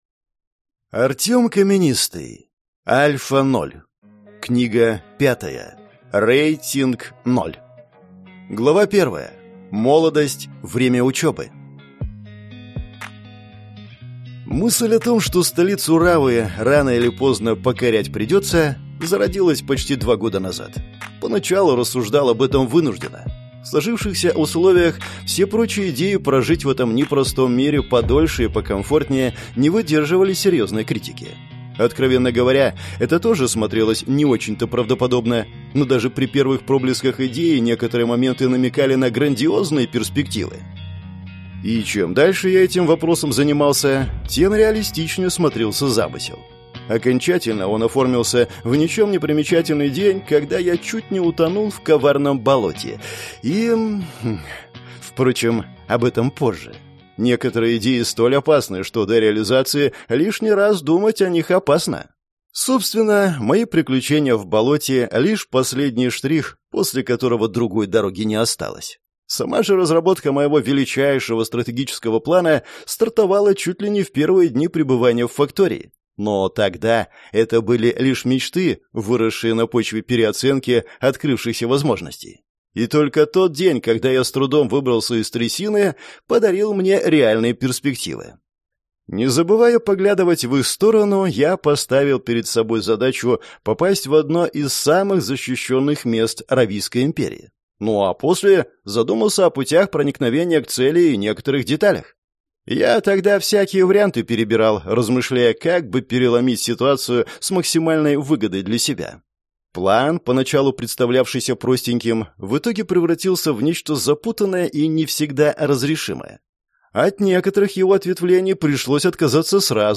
Аудиокнига Рейтинг-ноль | Библиотека аудиокниг